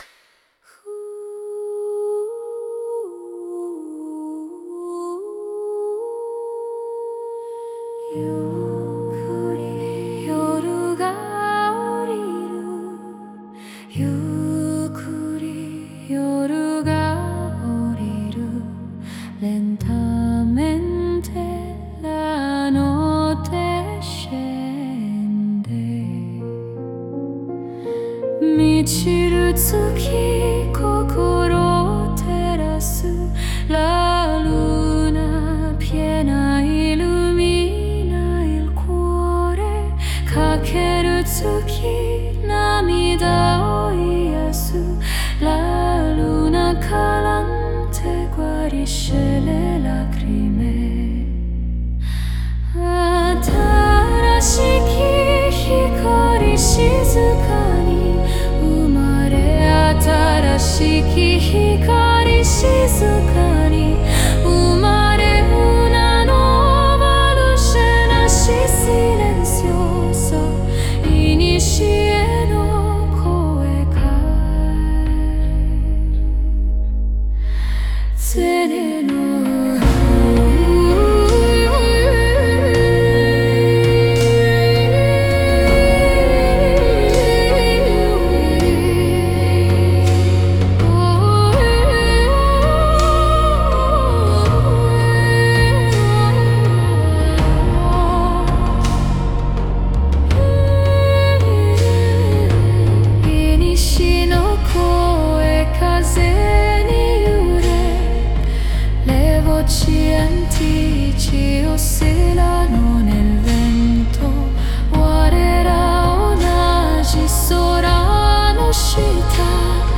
È una musica che non invade, ma accompagna.
Frequenze: 396 Hz · 432 Hz · 528 Hz